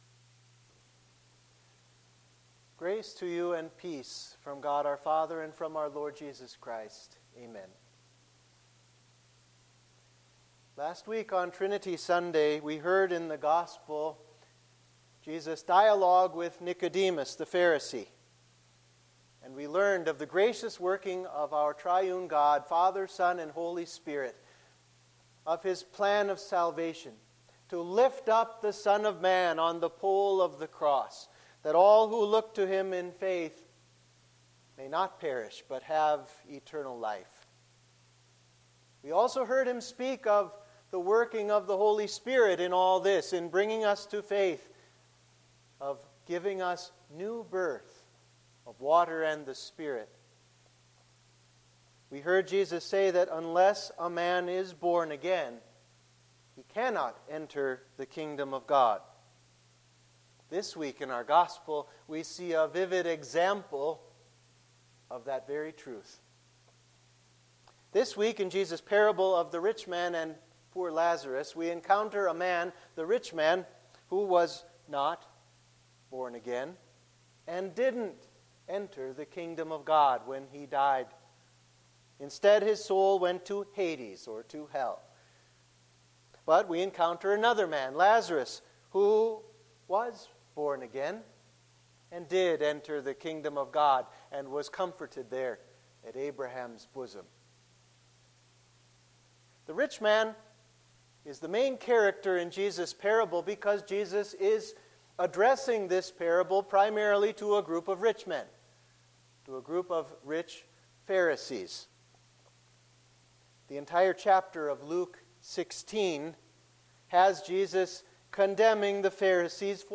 Sermon for Trinity 1